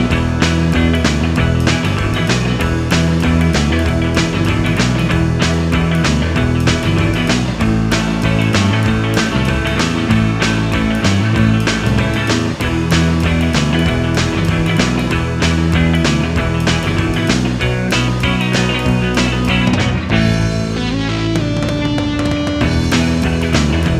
No Backing Vocals Rock 3:54 Buy £1.50